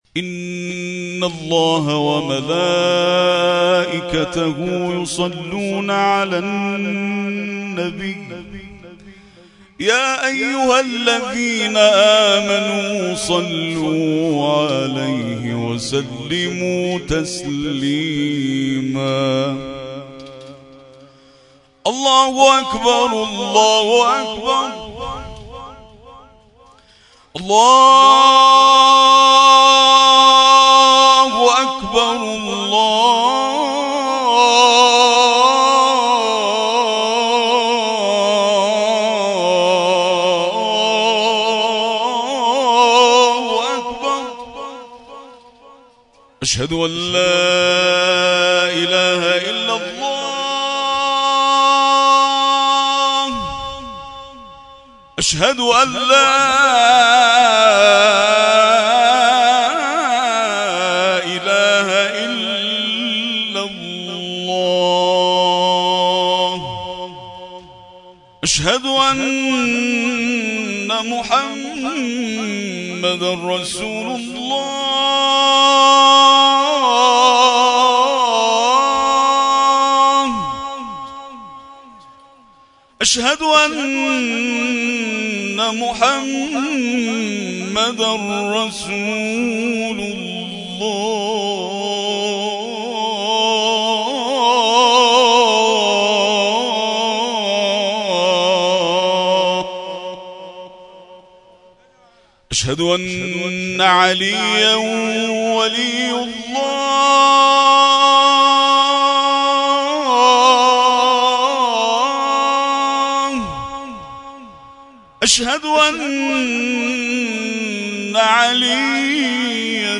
در این مراسم علاوه بر مرثیه‌خوانی ذاکران اهل بیت عصمت و طهارت، قاریان ممتاز و بین‌المللی کشورمان نیز به تلاوت پرداختند.
ابتهال‌خوانی